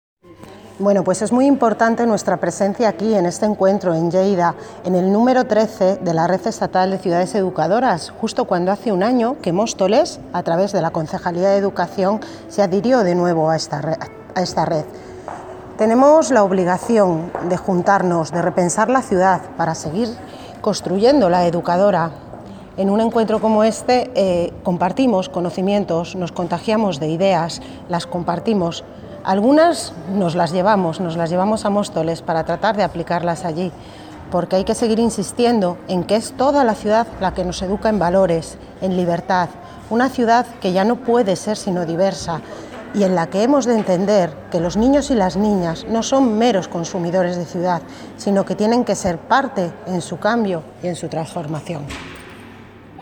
Audio - Isabel Cruceta (Concejal de Educación) Sobre XIII Encuentro de la Red Estatal de Ciudades Educadoras